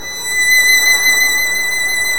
Index of /90_sSampleCDs/Roland - String Master Series/STR_Violin 1-3vb/STR_Vln1 _ marc